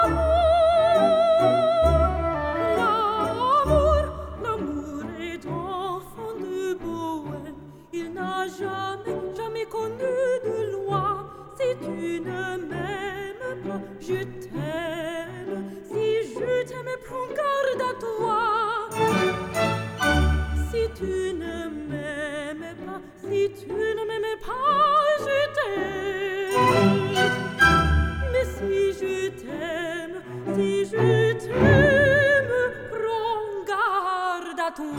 Classical Crossover
Жанр: Классика